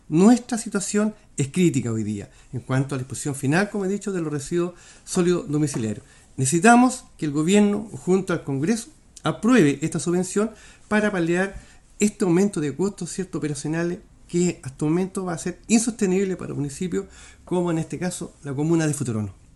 El alcalde de Futrono, Claudio Lavado, emplazó al Gobierno e hizo un llamado al Congreso Nacional a aprobar el subsidio, recalcando que la situación es “crítica” en la disposición final de basura.